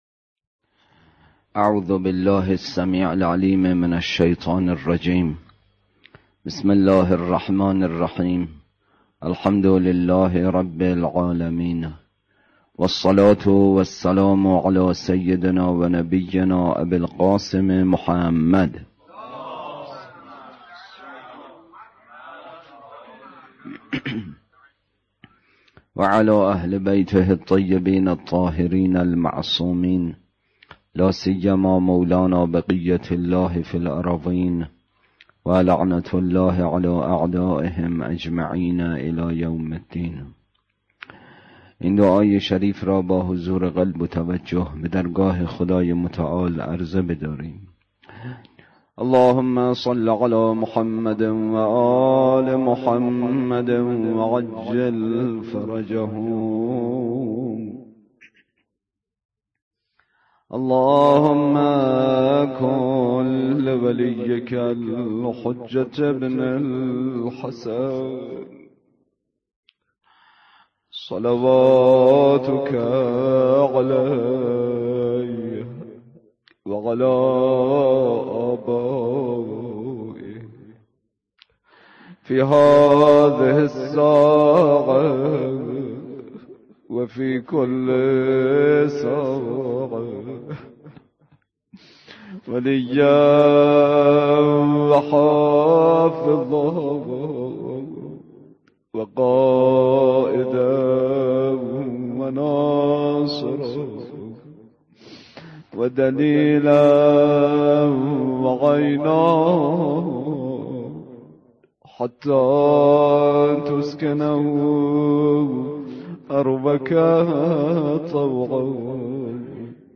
اطلاعات آلبوم سخنرانی